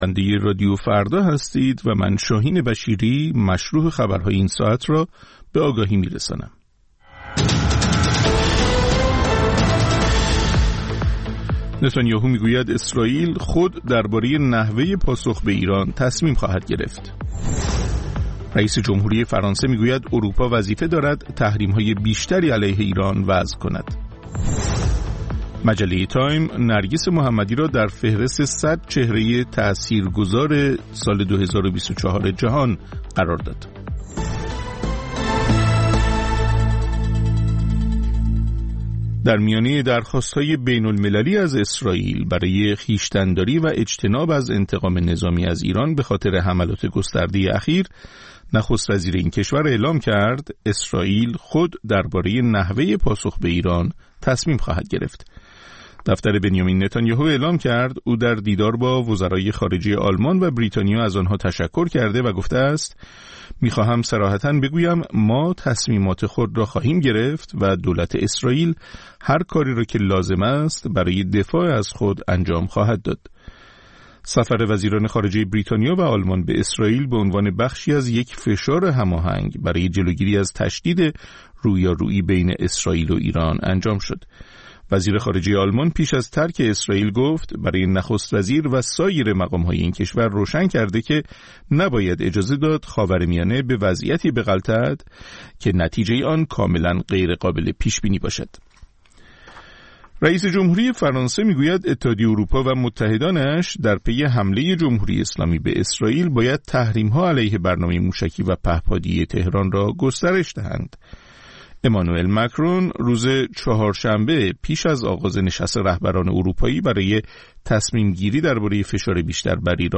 سرخط خبرها ۲:۰۰